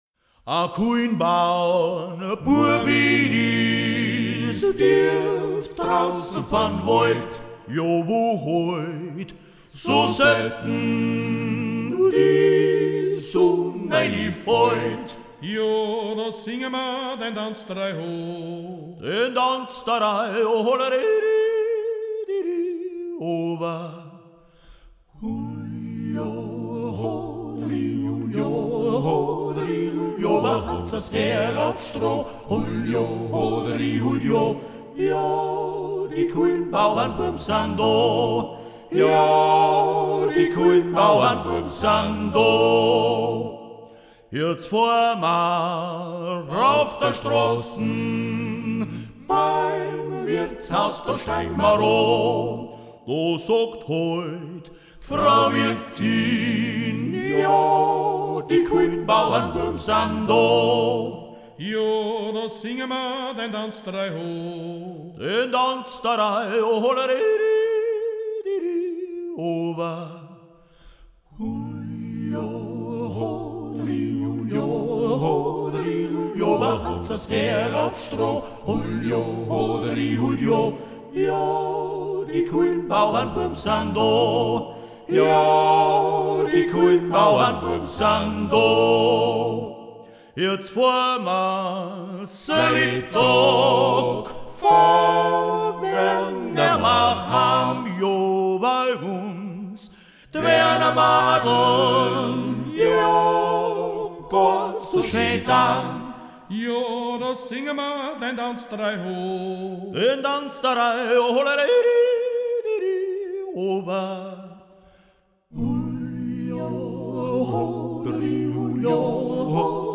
Jodler und Jodler-Lied – Ungeradtaktig
Yodel, Yodel-Song – triple metre (3/4): Lower Austrian and Styrian Wechsel-region; social structure; local dialect
Folk & traditional music